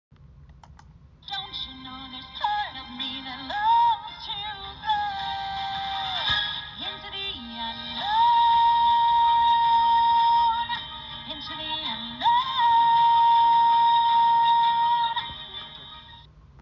Grosser singender Folienballon
• 🎶 Mit Musikfunktion – sorgt für Party-Stimmung